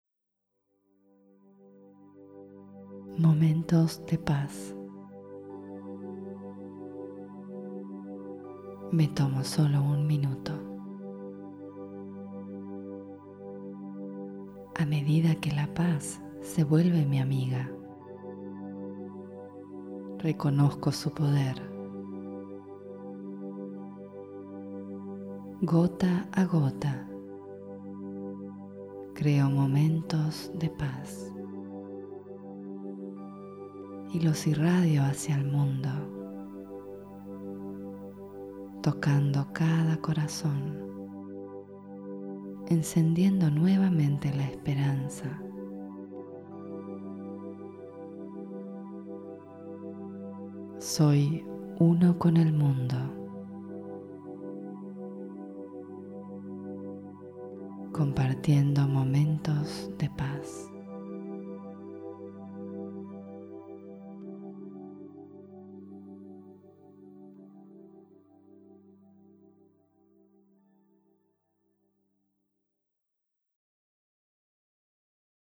Meditaciones Guiadas – Brahma Kumaris Chile